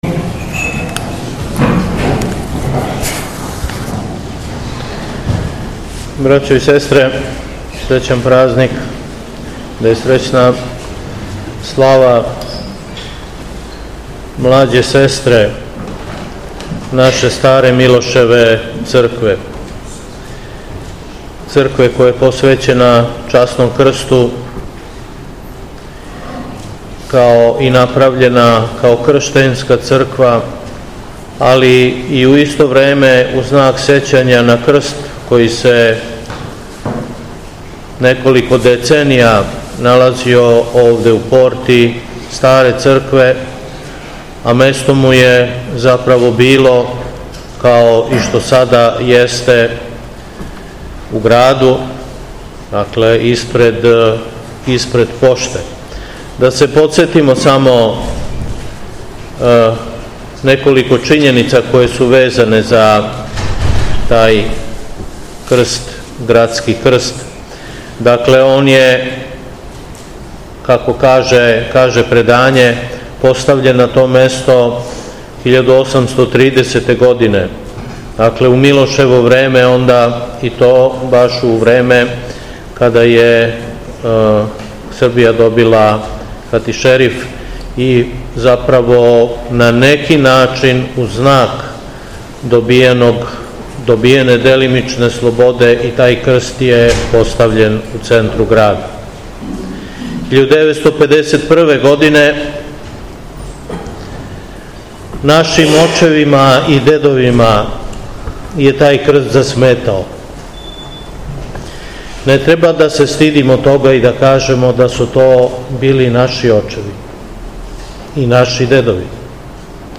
У среду 14. августа 2024. године, Његово Високопресвештенство Митрополит шумадијски Г. Јован служио је поводом Храмовне Славе Свету Архијерејску Литур...